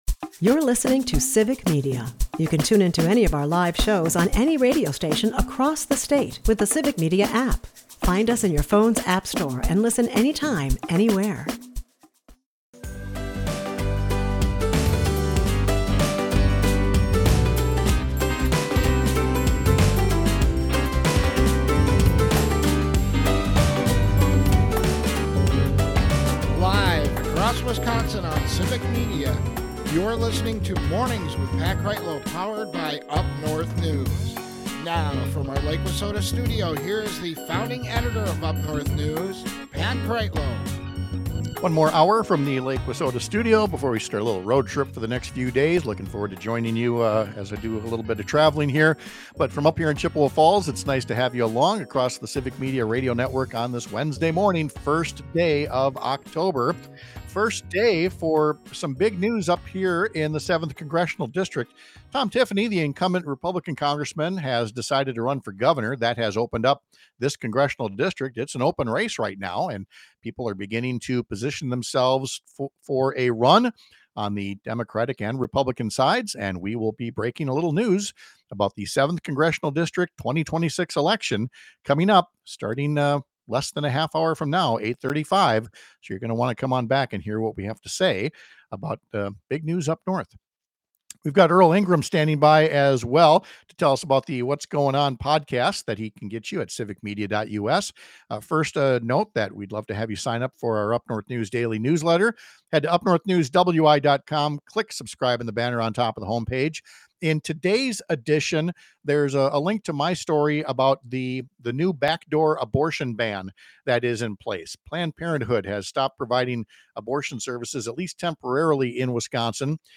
Mornings with Pat Kreitlow is powered by UpNorthNews, and it airs on several stations across the Civic Media radio network, Monday through Friday from 6-9 am.